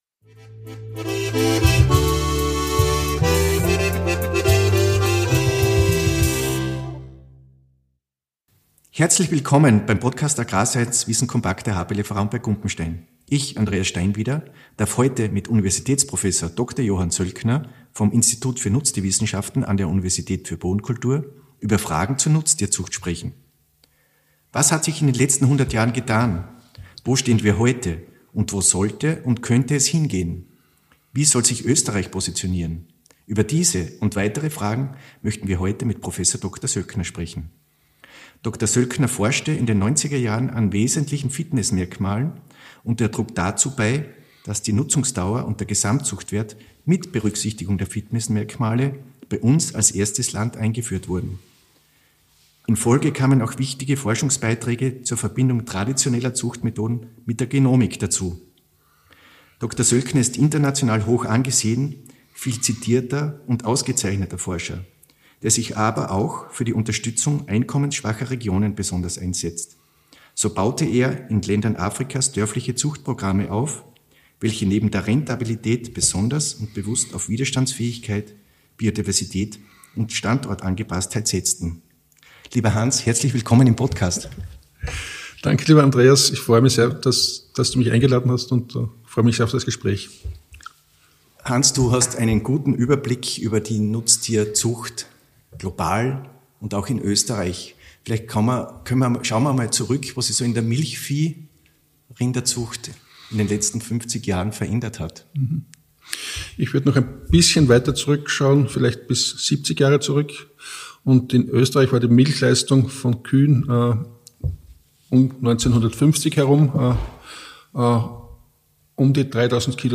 Im Podcast-Gespräch von Priv.-Doz.